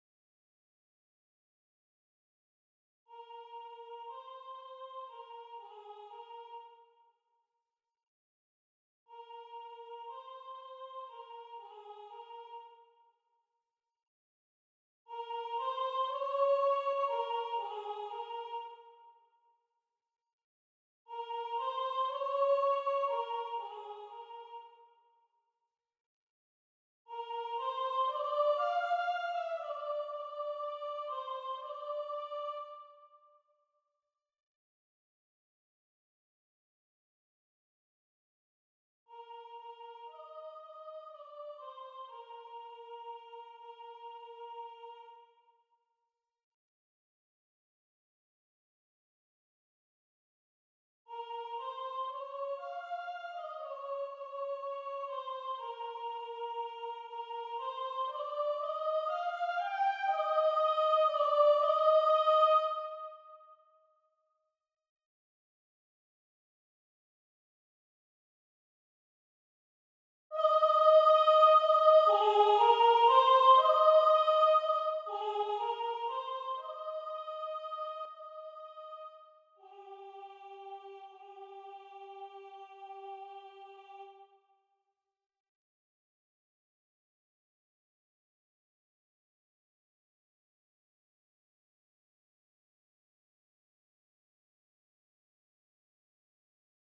soprán